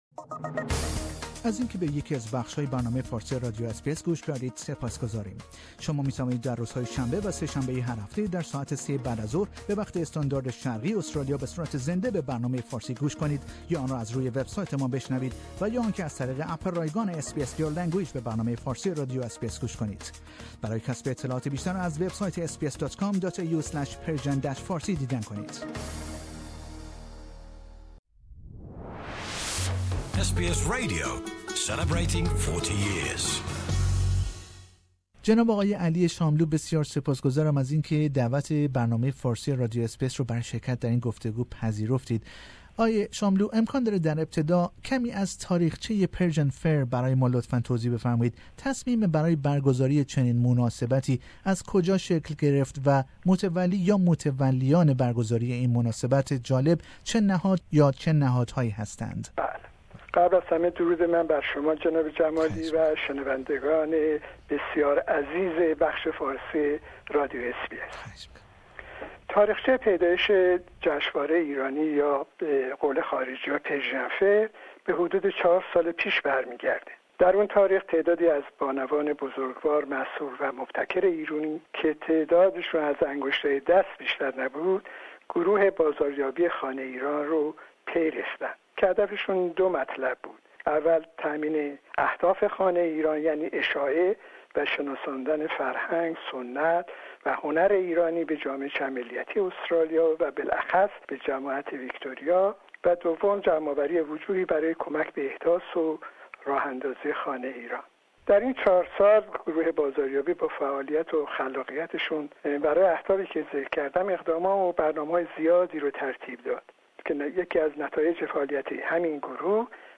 Persian Fair جشنواره ایرانی یا نام مناسبتی سالانه است که امسال برای چهارمین سال در ملبورن برگزار خواهد شد. در این جشنواره سالانه هنر، فرهنگ و غذای ایرانی به علاقمندان و دوستداران ایران زمین ارائه می شود. در گفتگو